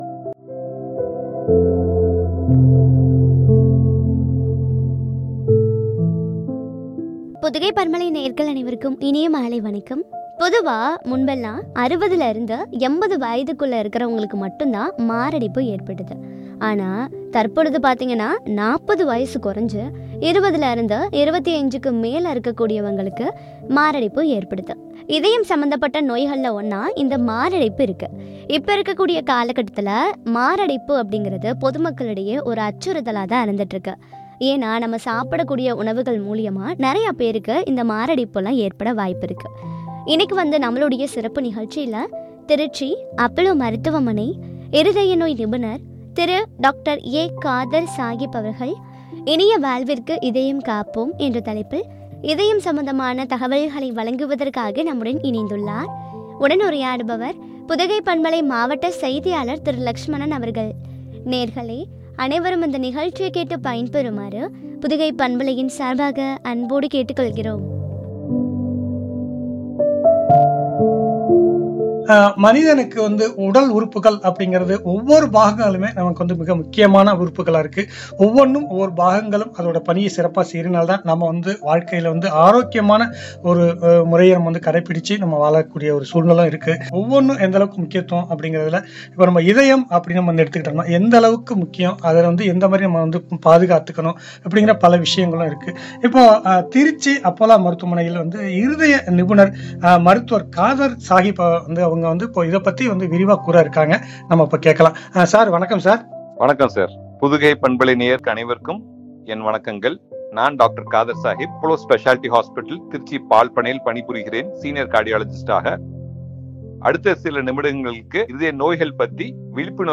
” இனிய வாழ்விற்கு, இதயம் காப்போம்” குறித்து வழங்கிய உரையாடல்.